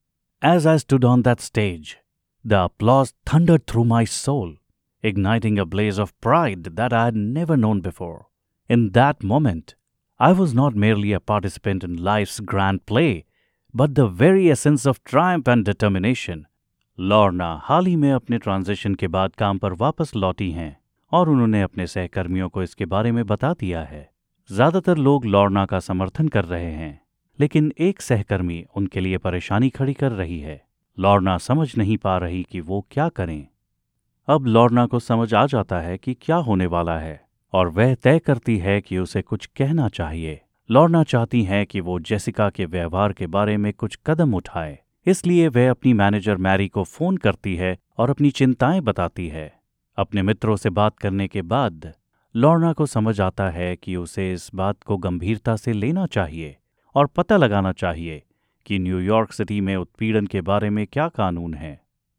Male
Approachable, Bright, Cool, Corporate, Friendly, Natural, Smooth, Soft, Warm
English Indian Accent (Native)
Microphone: Electro Voice RE20